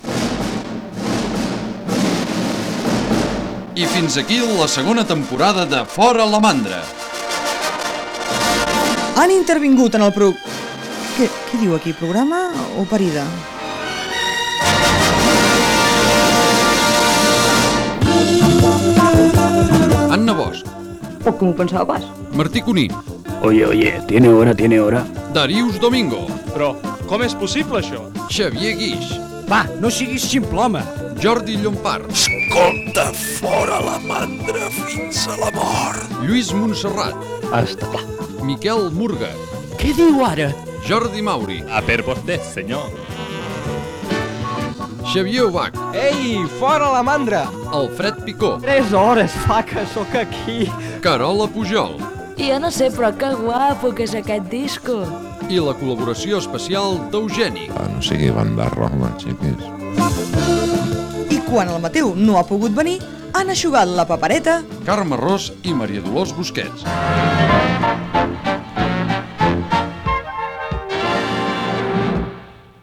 Comiat de la segona temporada del programa, amb els noms i veus de l'equip
Entreteniment